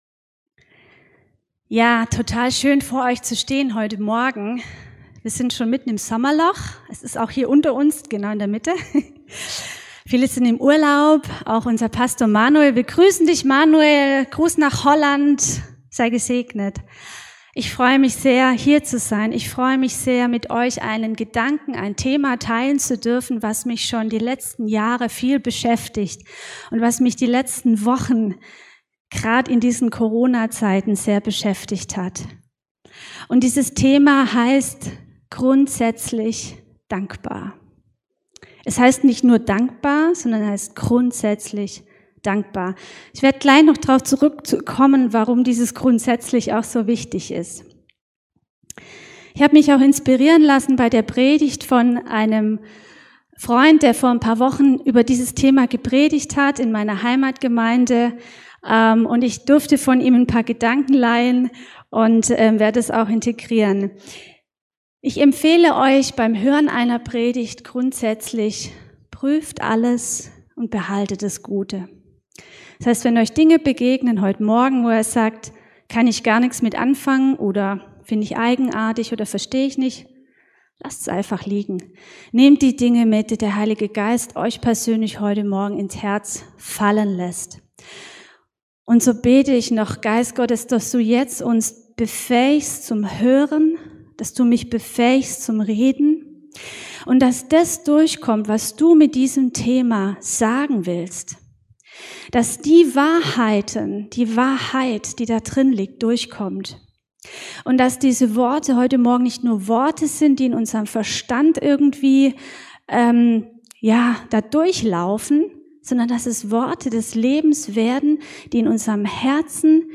Dankbar Gottesdienst